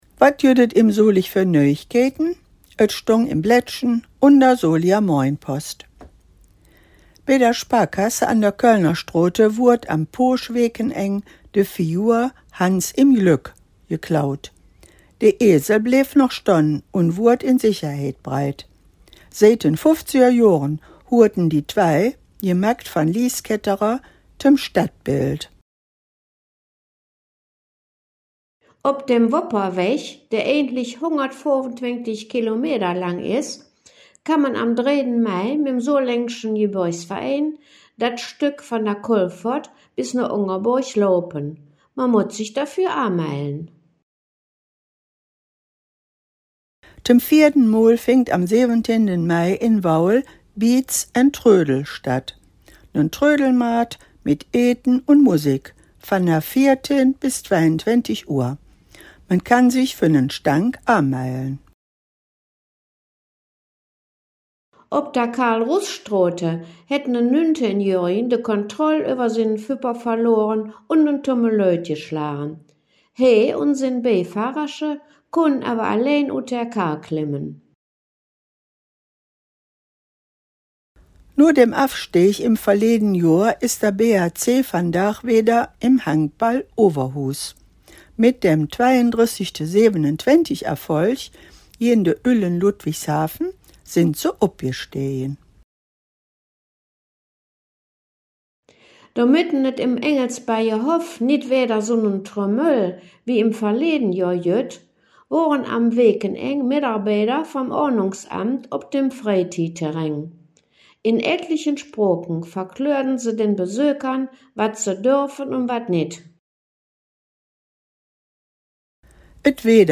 In dieser Folge "Dös Weeke em Solig" blicken de Hangkgeschmedden in Solinger Platt auf die Nachrichten vom 26 . April 2025 bis zum 01. Mai 2025 zurück.